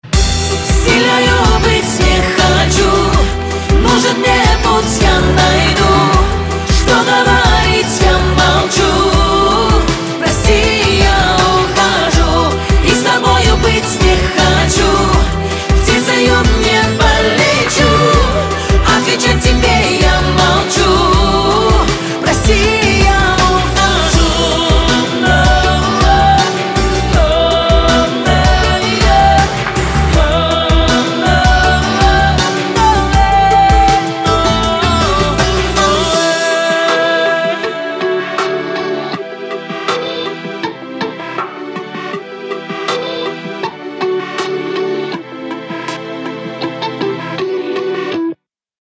• Качество: 256, Stereo
красивые
женский вокал
dance
спокойные